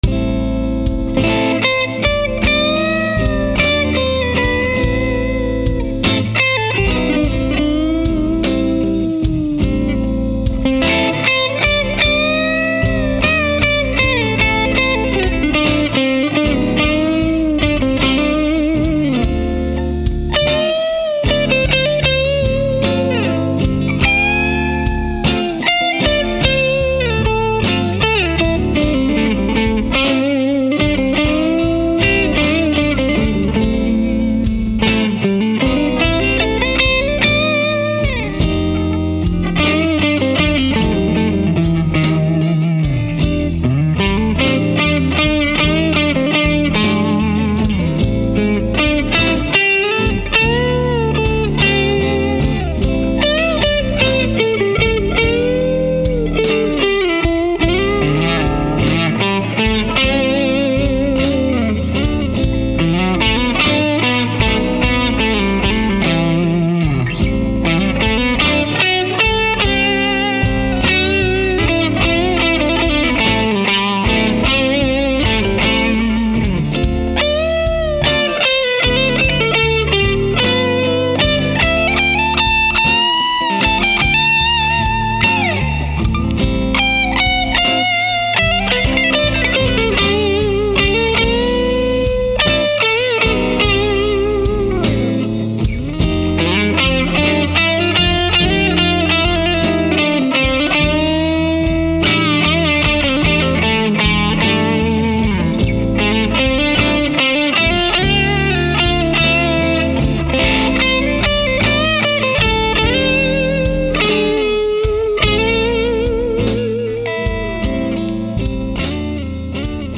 The first clip is slow, slow blues clip in Am.
The rhythm part was recorded with the KASHA Overdrive in Smooth with the gain set at 12 o’clock.
The first part features the Classic “channel,” and the second part features the Hot channel.
By the way, both clips were played through the clean channel of my Aracom VRX22, and recorded at conversation levels using the incredible Aracom PRX150-Pro attenuator.